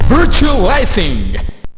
-The voice that yells "Virtua Racing" has been changed.